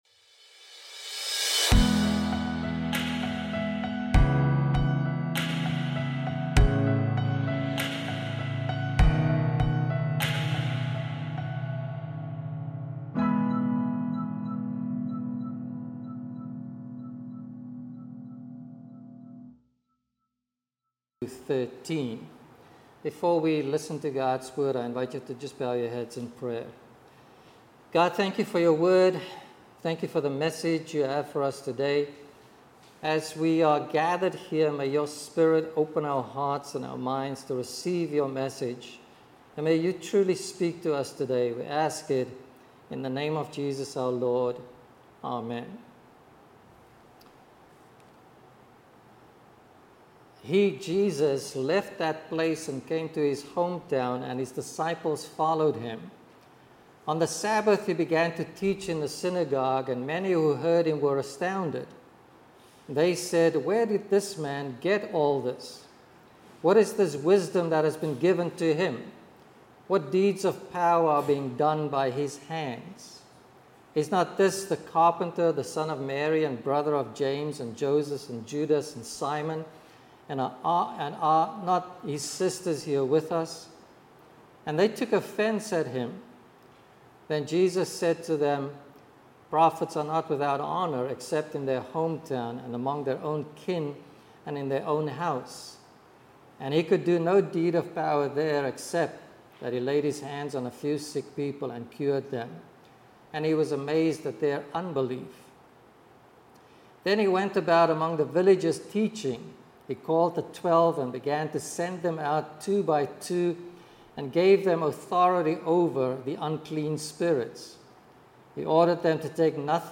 Sermons | Community Church of Douglaston